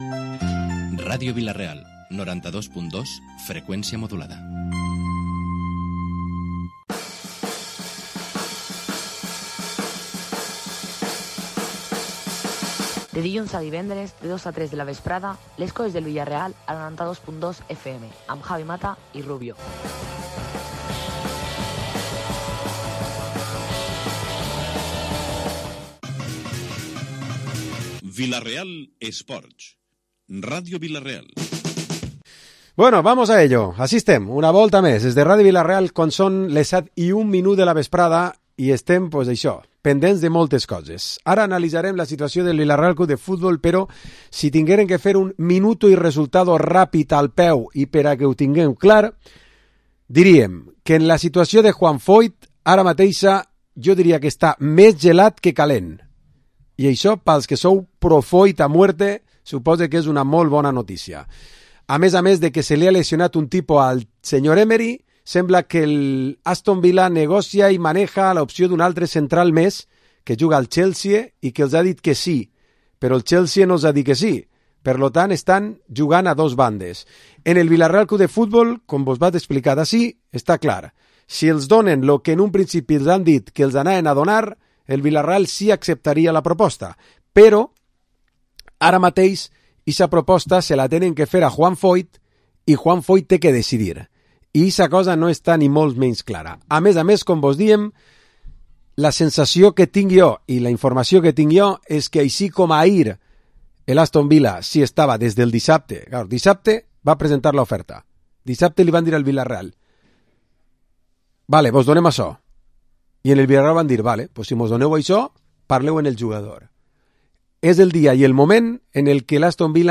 Programa Esports dilluns tertúlia 27 de gener